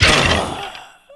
Skeleton Bodyfall Sound
horror